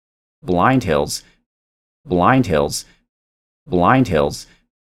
Clicks/Beeps in Recorded Audio w. Video Examples
The “long S’s” are excessive-sibilance which can be attenuated with a De-Esser plugin .